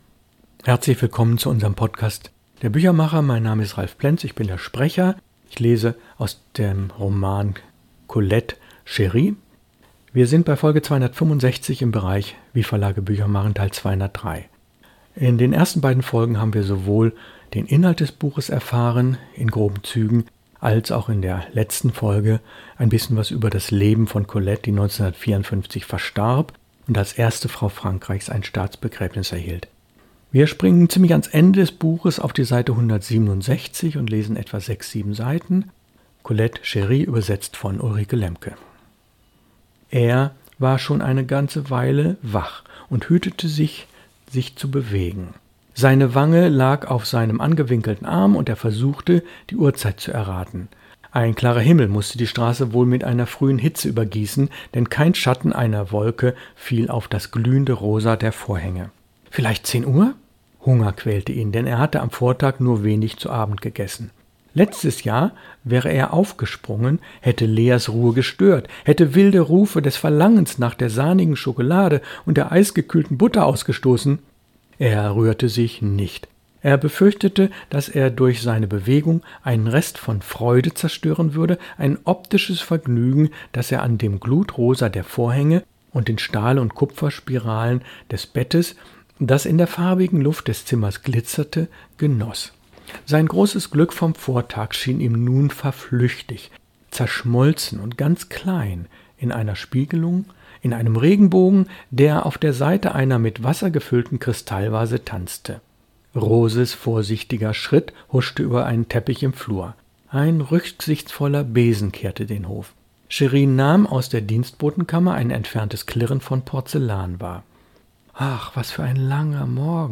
Lesung aus: Colette – Chéri, Folge 3 von 3